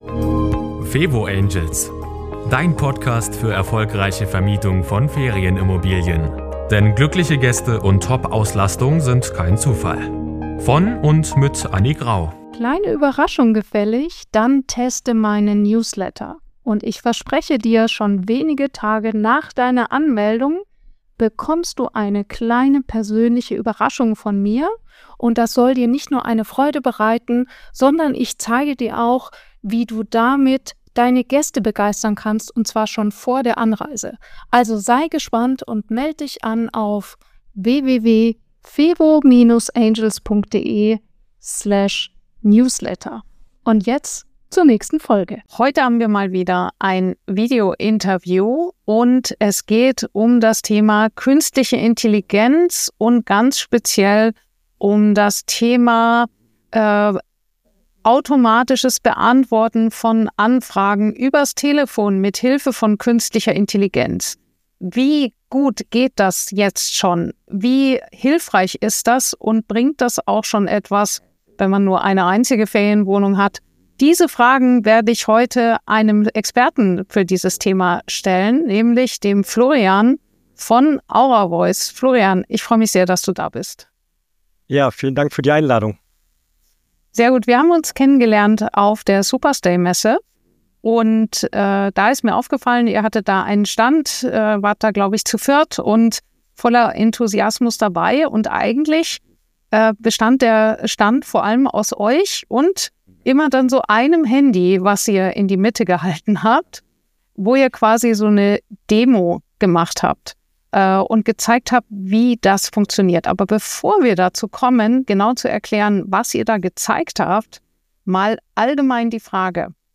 Interview
Außerdem testen wir live, wie ein KI-basierter Telefonassistent funktioniert und was er für Dich leisten kann.